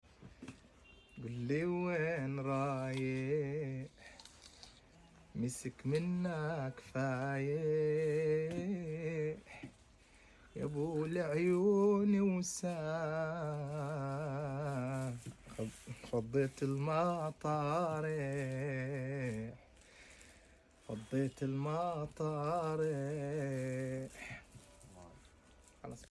أنشد هذه الكلمات وكأنه يودعنا.